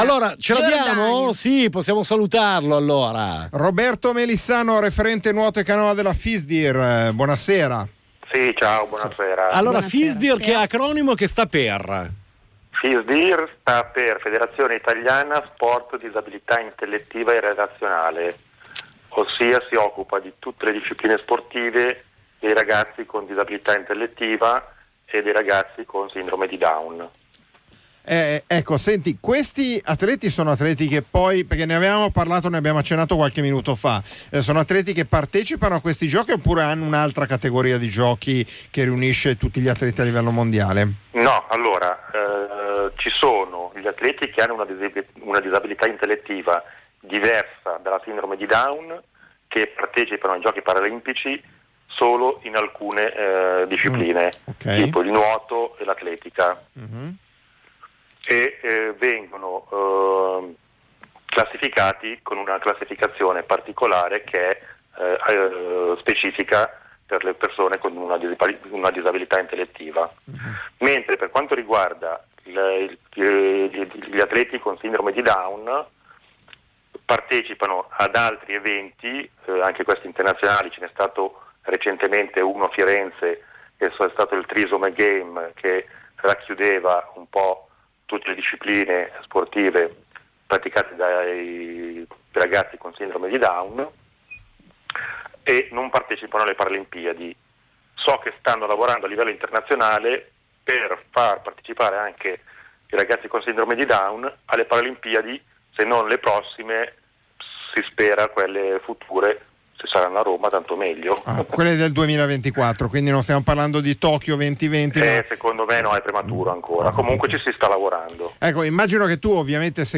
nel corso della trasmissione PodiPodi in occasione della cerimonia di apertura delle para olimpiadi di rio 2016